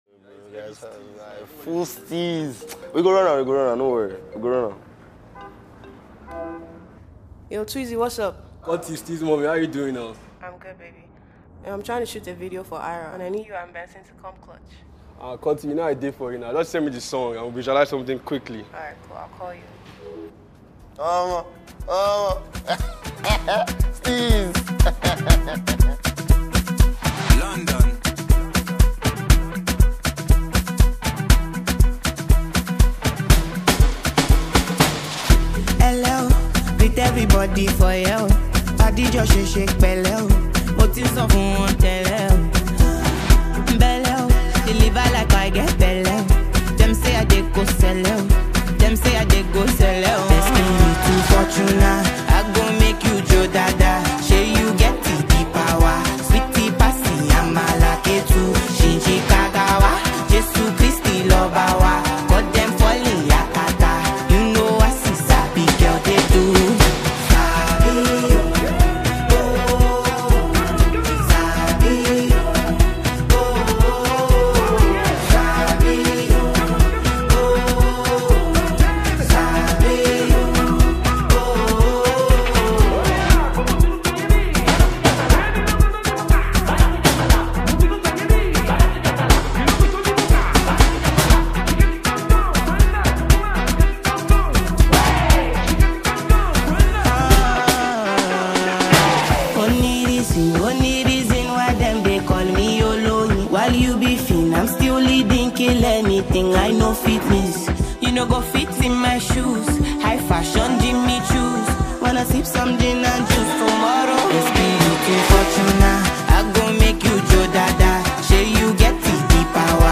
gifted female singer